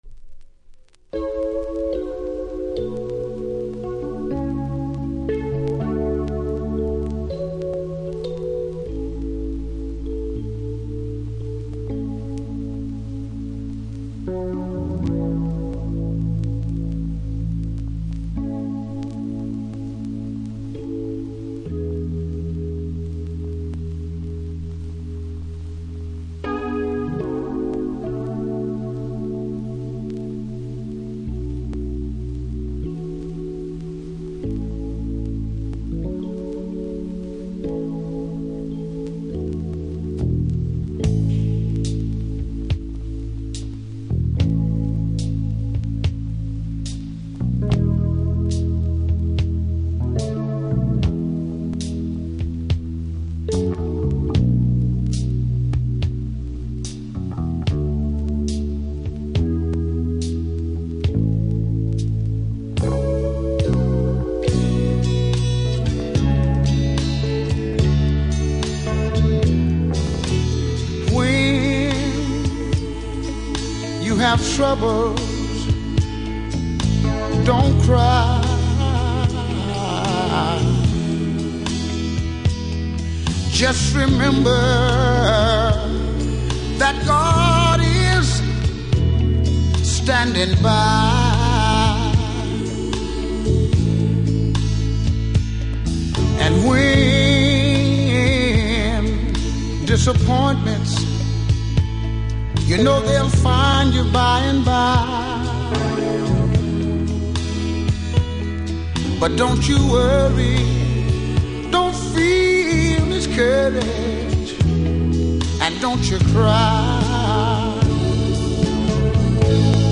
盤は見た目綺麗ですが少しノイズ感じますので試聴で確認下さい。